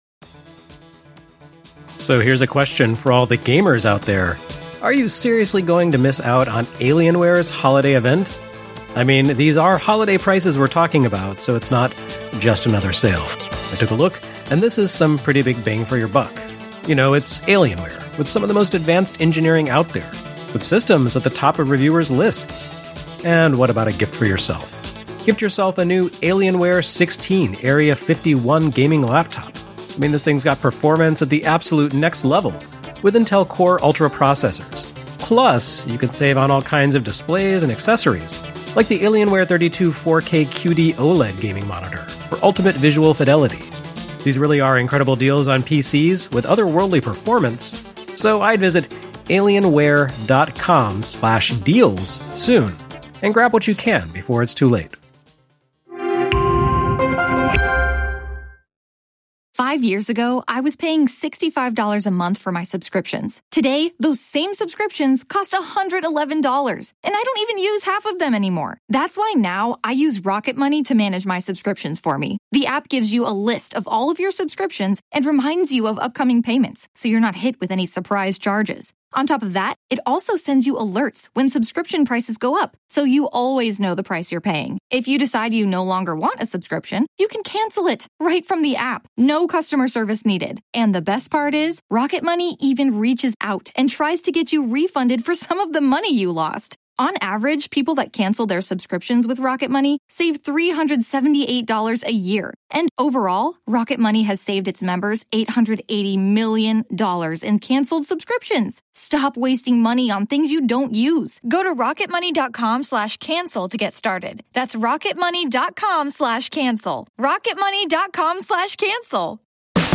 In an episode that's nearly retro itself, we go back to GDC 2018 for an interview with Zynga's Mark Turmell on his historic work, including NBA Jam, NFL Blitz, Hasbro's failed NEMO console, and the spiritual connection between arcade and mobile gaming.